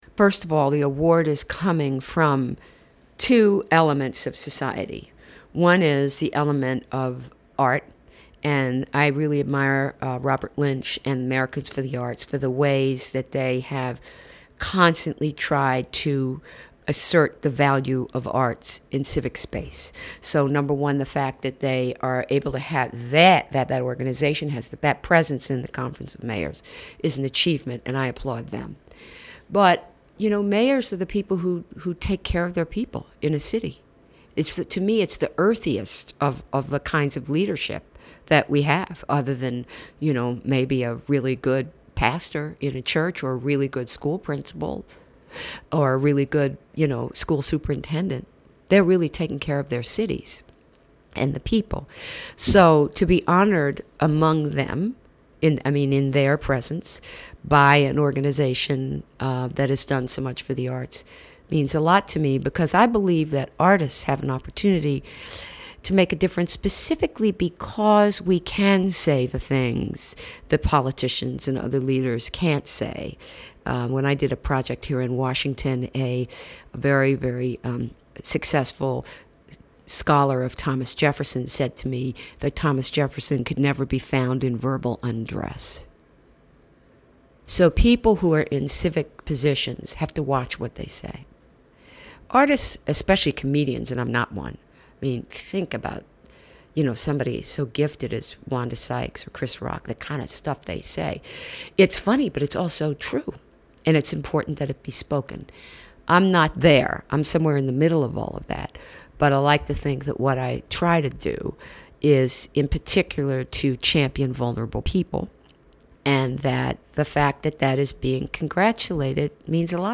Transcript of conversation with Anna Deavere Smith